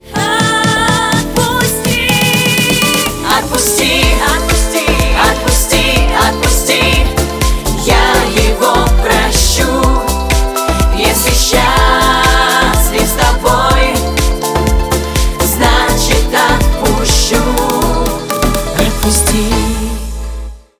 • Качество: 128, Stereo
поп
громкие
дуэт